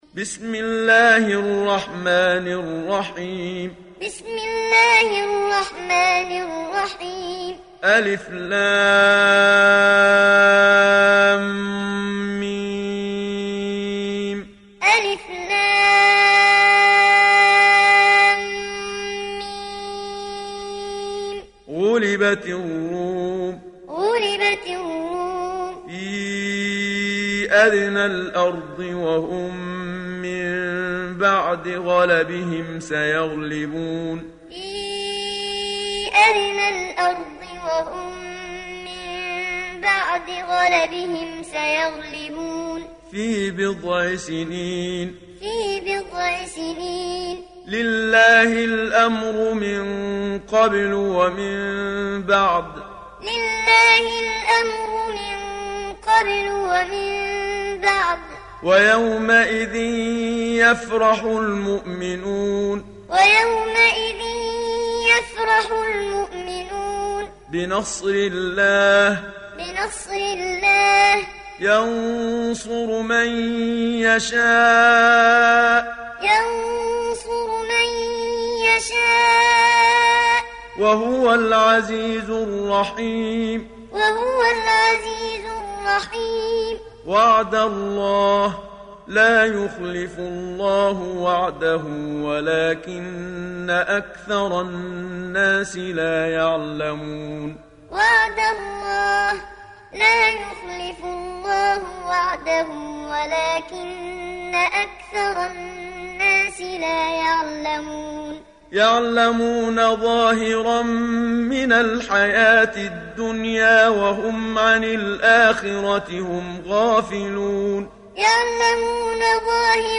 Sourate Ar Rum Télécharger mp3 Muhammad Siddiq Minshawi Muallim Riwayat Hafs an Assim, Téléchargez le Coran et écoutez les liens directs complets mp3